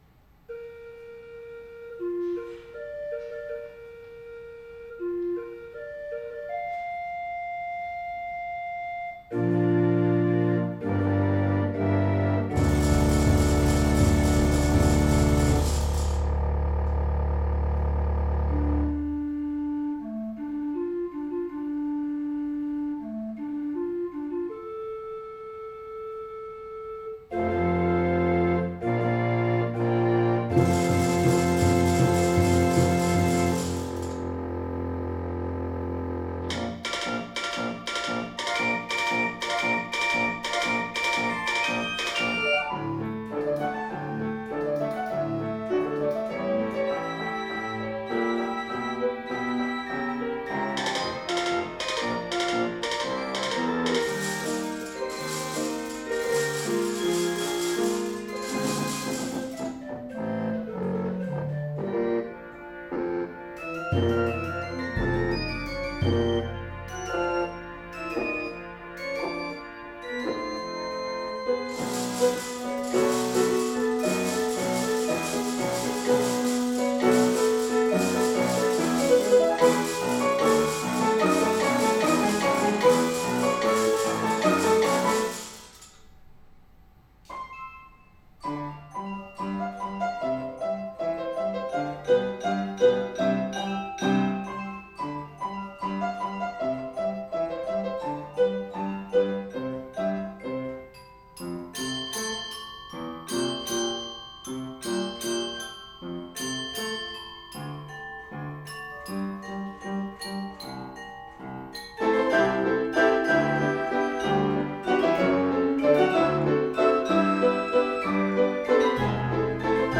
25.01.2026: De opnames van ons nieuwjaarskoncert zijn nu downloadbaar vanop onze site.